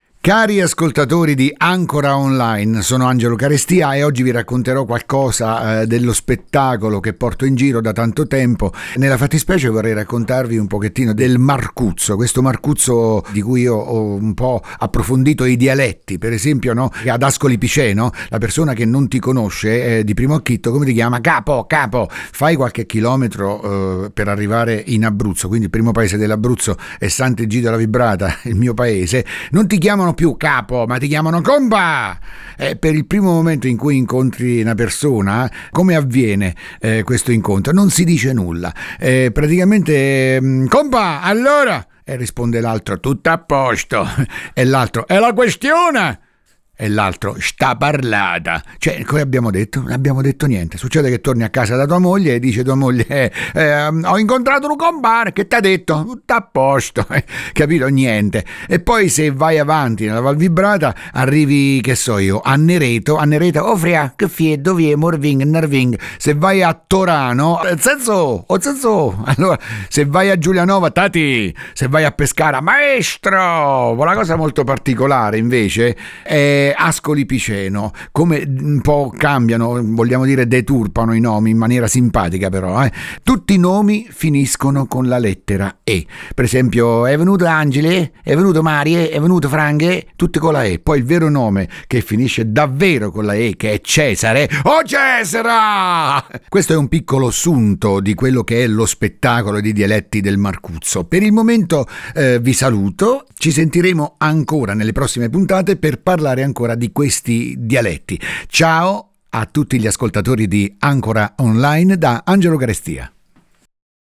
Tra le sue voci più sorprendenti quella dell’indimenticabile Principe della risata Totò che raggiunge il massimo della spettacolarità nell’esecuzione impeccabile della celebre “Miss mia cara miss”. Oltre a Totò, cantanti attori e politici.